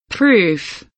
proof kelimesinin anlamı, resimli anlatımı ve sesli okunuşu